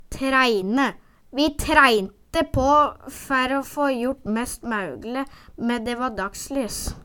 træine - Numedalsmål (en-US)